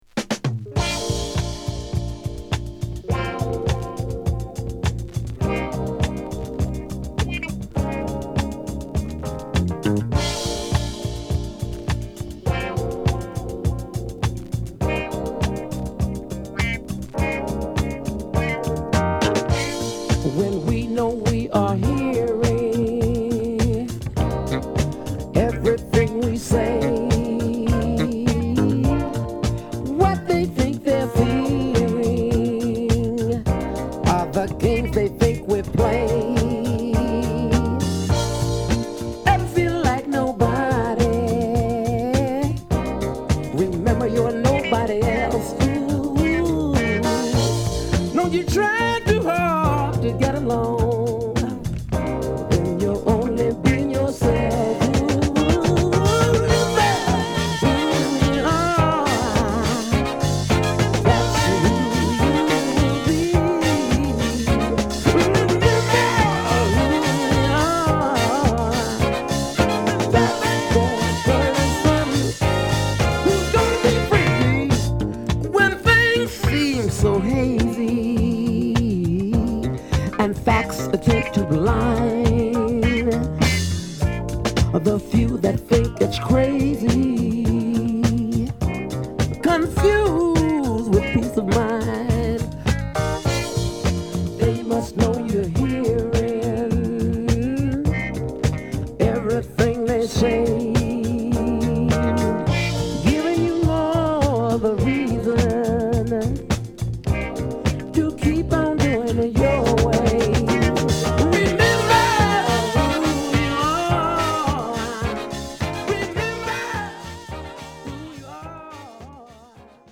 モダンソウルやブギー好きにも是非。